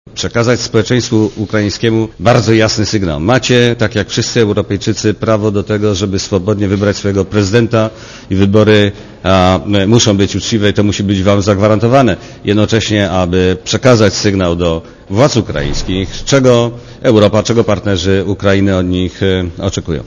Posłuchaj komentarza Włodzimierza Cimoszewicza